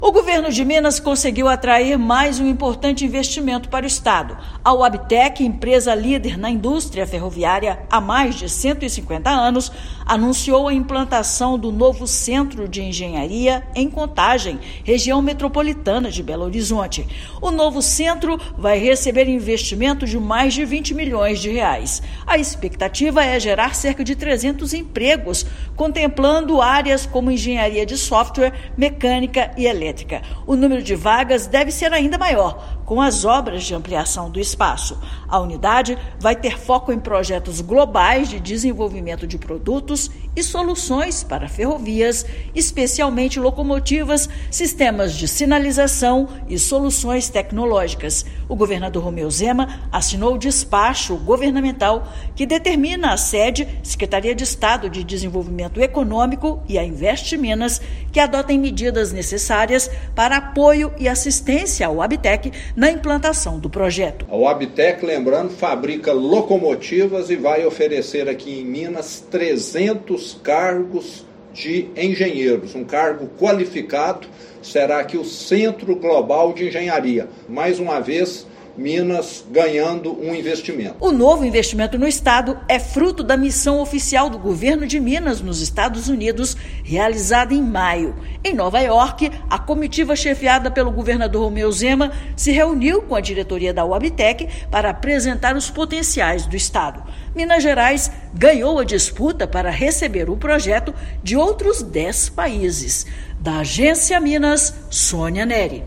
Empresa Wabtec anuncia construção de novo centro de engenharia em Contagem após Minas vencer concorrência com estados de dez países. Ouça matéria de rádio.